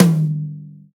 tom-high.wav